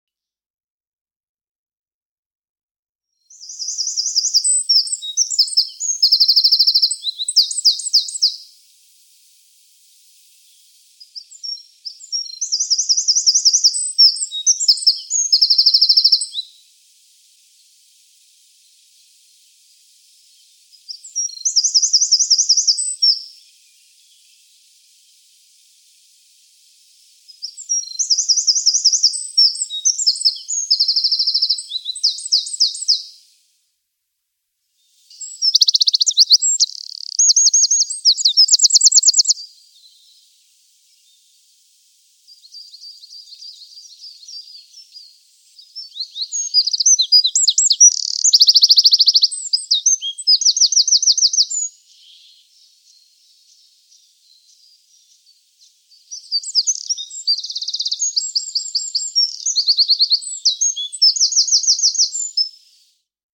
Wasseramsel und Zaunk�nig
Auch der Zaunk�nig lebt mit Vorliebe am Wasser, wo er sich im Wurzelwerk oder Gestr�pp gut verstecken kann und seine Brutst�tte vor Feinden sicher ist. Er ist ein munterer Geselle und seine kr�ftigen Roller, die er auch im strengsten Winter h�ren l�sst, traut man dem kleinen Kerl gar nicht zu.